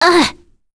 Leo-Vox_Damage_01.wav